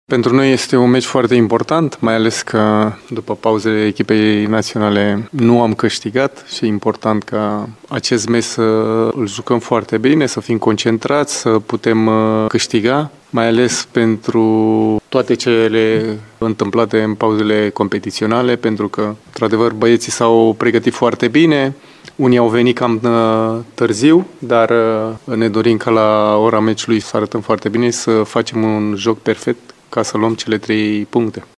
Antrenorul oltenilor, Constantin Gâlcă, amintește că Universitatea a avut rezultate modeste după pauzele de echipă națională: